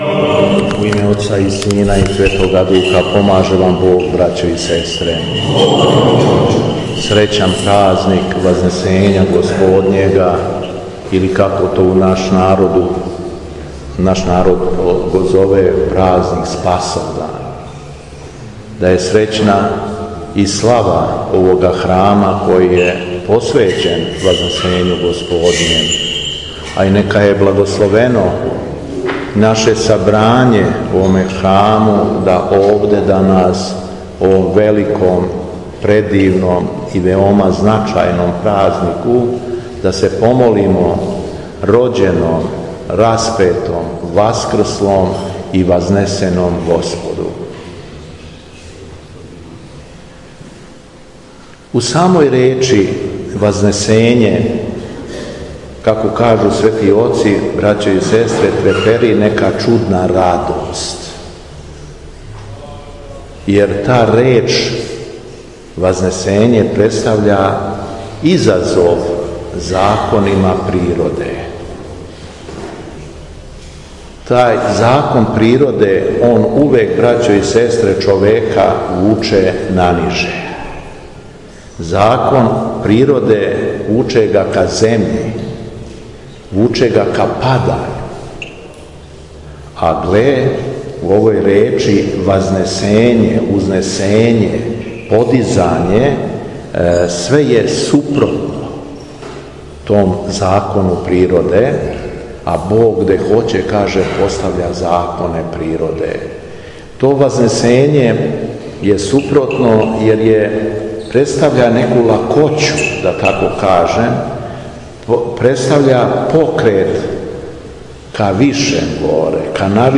Беседа Његовог Преосвештенства Епископа шумадијског г. Јована
Честитавши празник Спасовдан окупљеним верницима и храмовну славу мештанима села Рудовци, Владика Јован је богонадахнуто беседио: